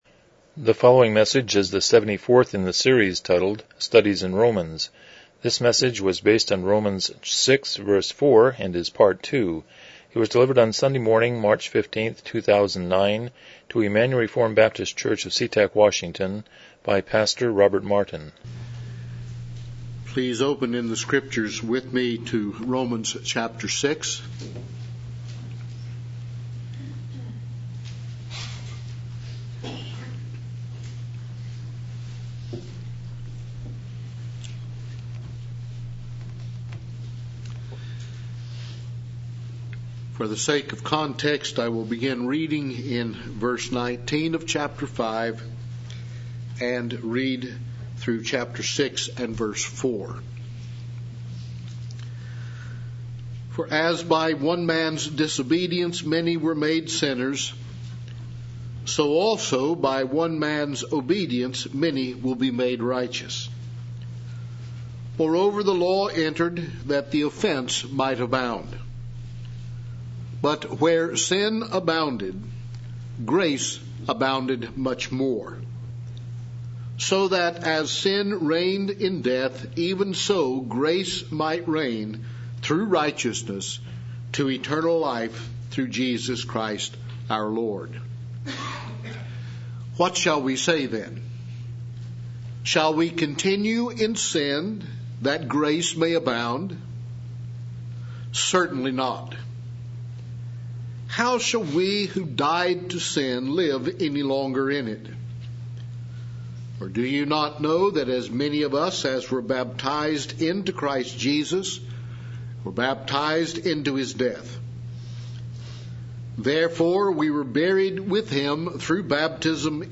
Romans 6:4 Service Type: Morning Worship « 24 Lamech and Enoch